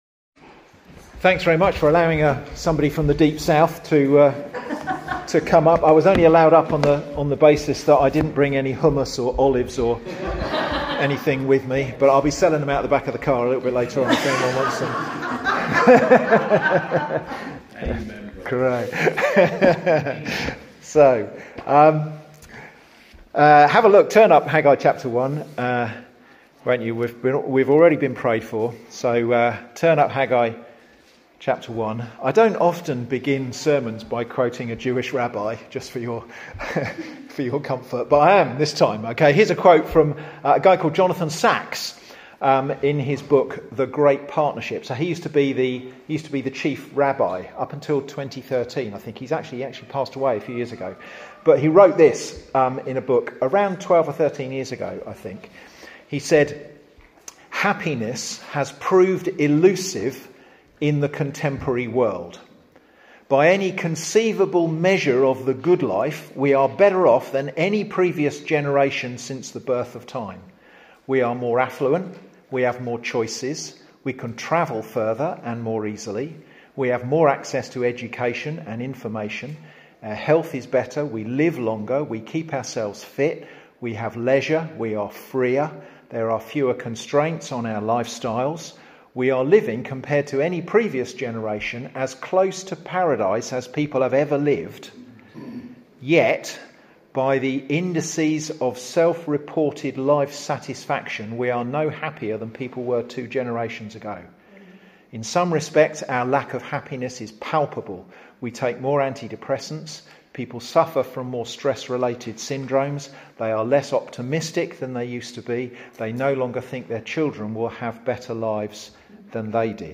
Away Day Sermon 1